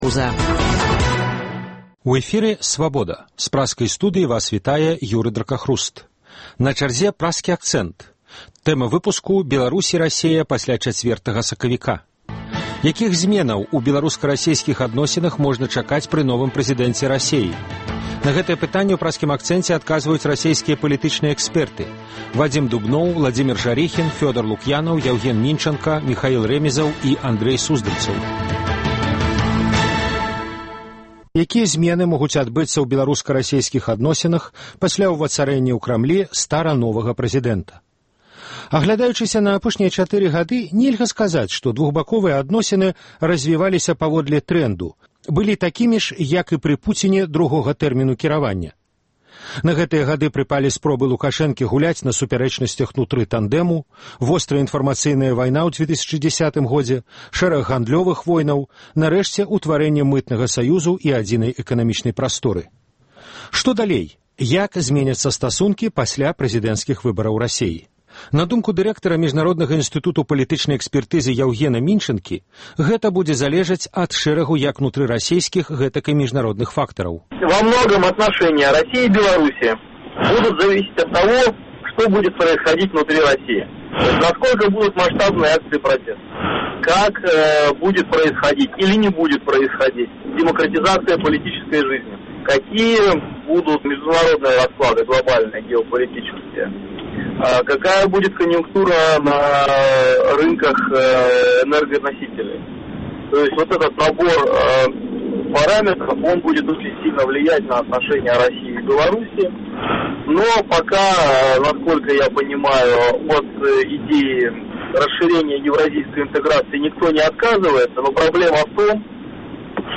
Якіх зьменаў у беларуска-расейскіх адносінах можна чакаць пры новым прэзыдэнце Расеі? Якую ролю адыгрывае і можа адыграць Расея ў цяперашнім канфлікце паміж афіцыйным Менскам і Эўразьвязам? На гэтыя пытаньні адказваюць расейскія палітычныя экспэрты